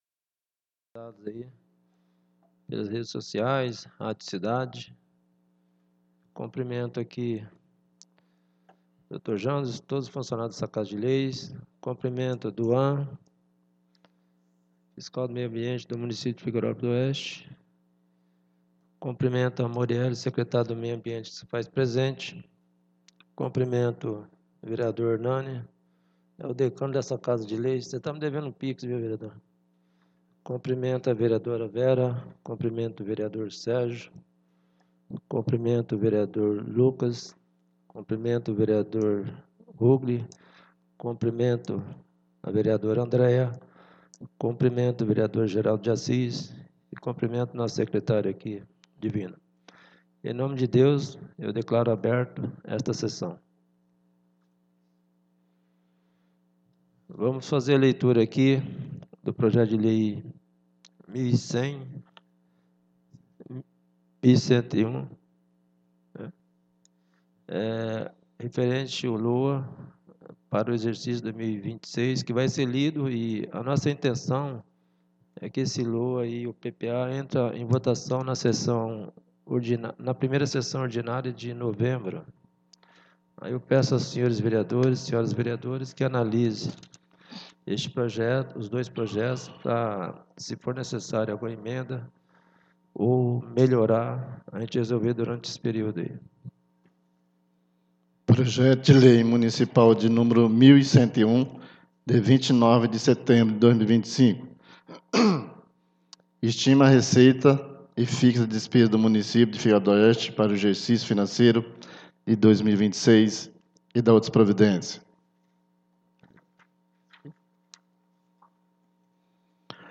14° SESSÃO ORDINÁRIA DIA 06 DE OUTUBRO DE 2025